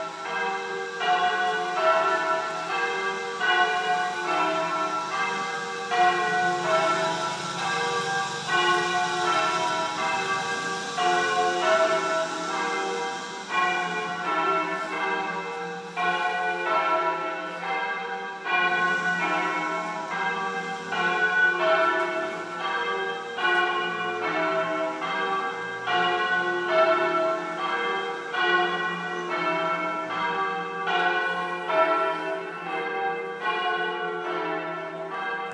Bells in barga